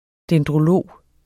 Udtale [ dεndʁoˈloˀ ]